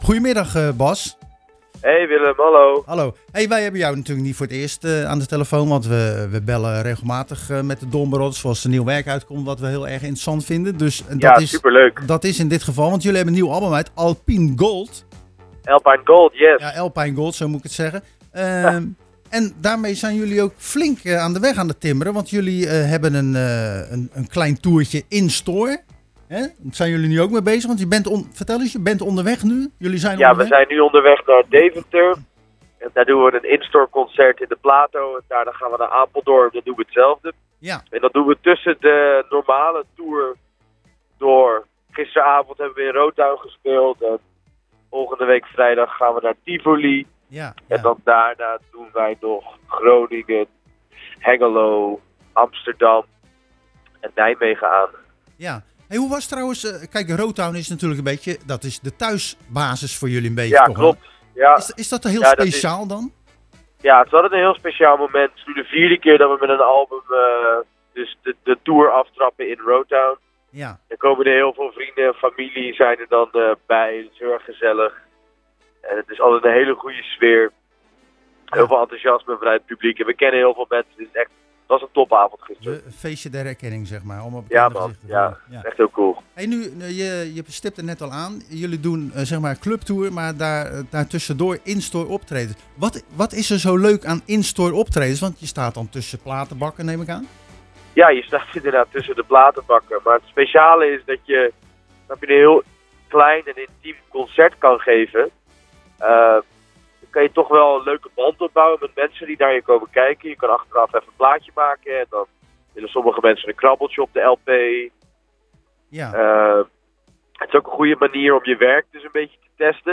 Tijdens de wekelijkse Zwaardviseditie belde we de de Rotterdammers van de Dawn Brothers n.a.v. van hun onlangs verschenen� 5e album Alpine Gold. De band was onderweg naar Deventer en Apeldoorn voor twee instore optredens.�Dat de band het druk heeft blijkt wel uit het tourschema.